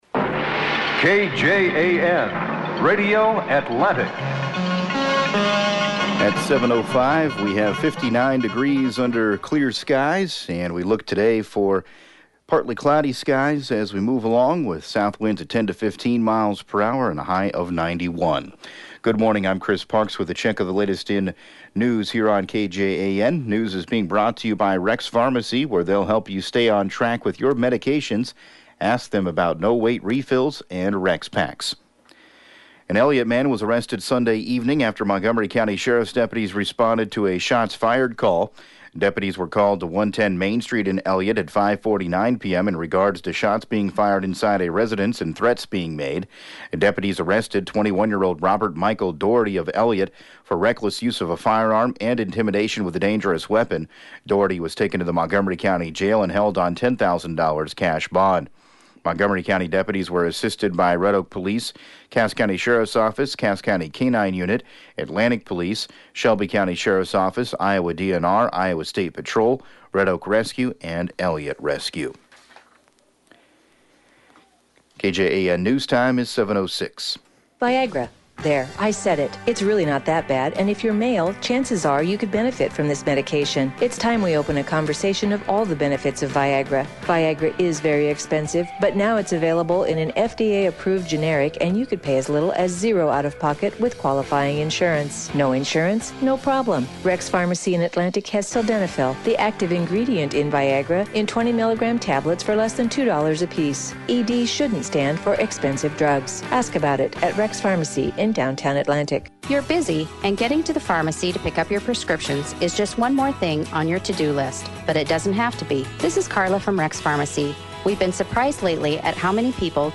7AM Newscast 08/13/2018